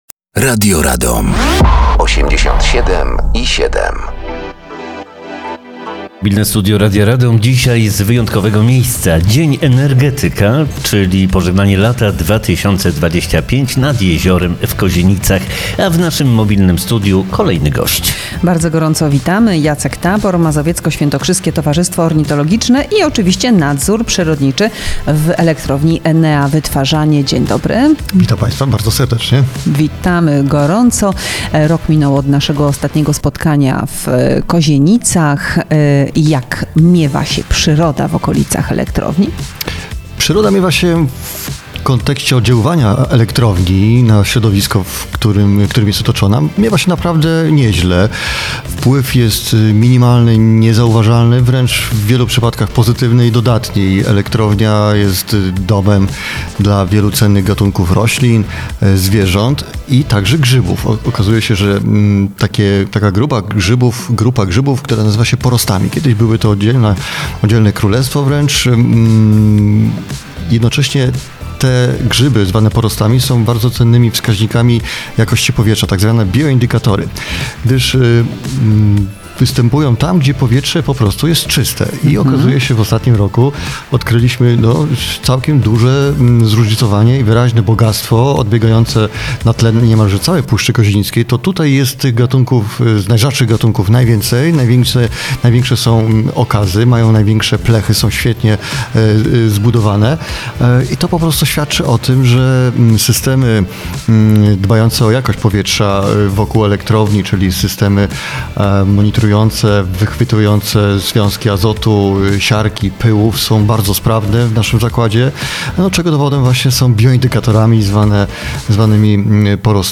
Dzień Energetyka 2025